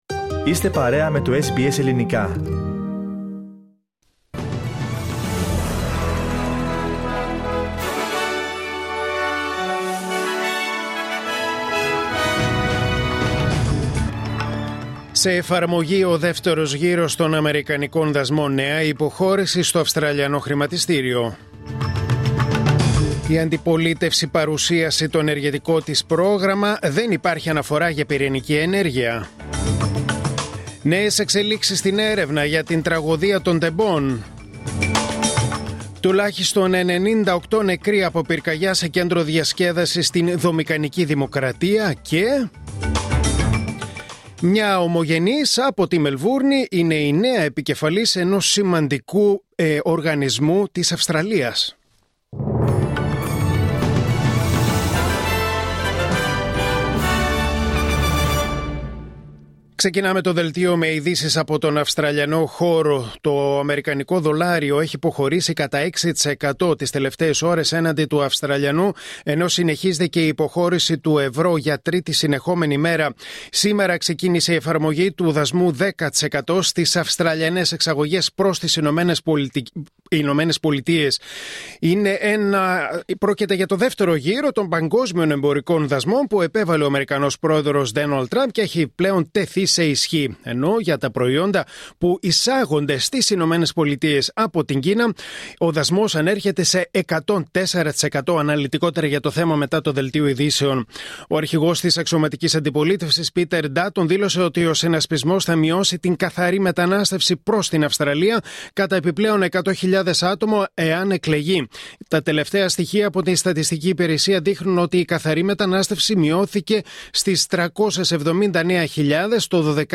Δελτίο Ειδήσεων Τετάρτη 09 Απριλίου 2025